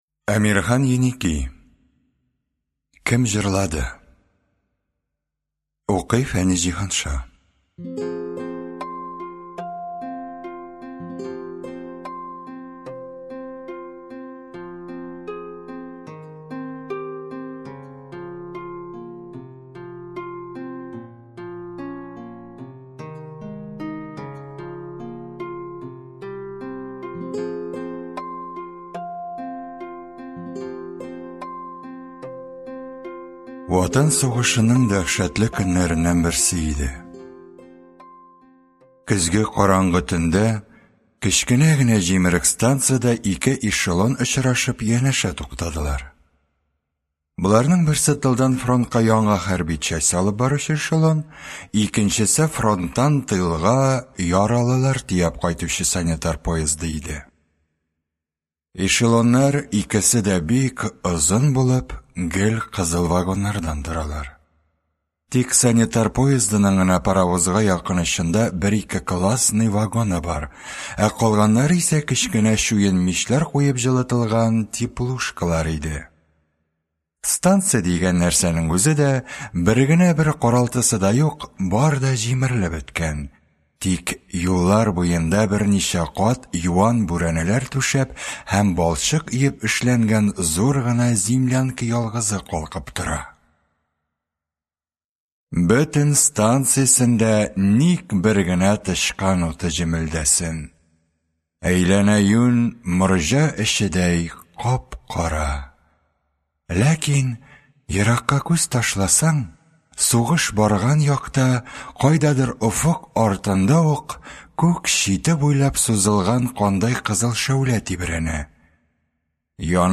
Аудиокнига Кем җырлады?